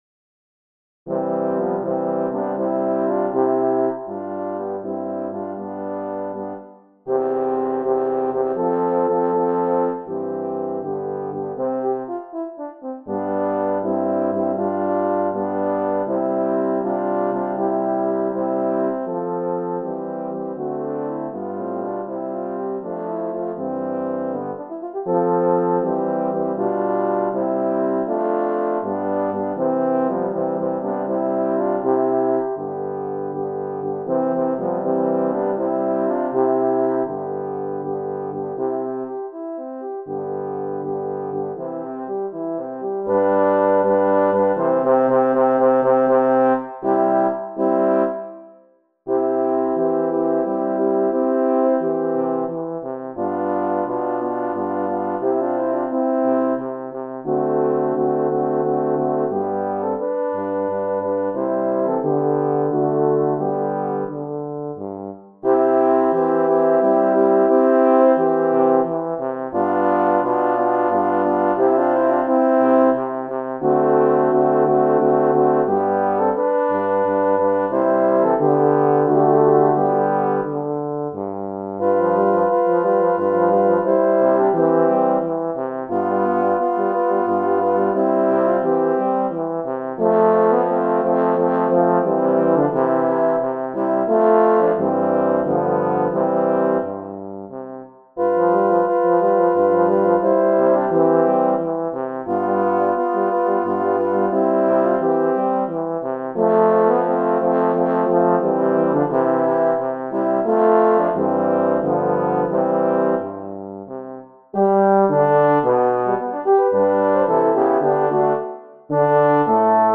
Voicing: Horn Quartet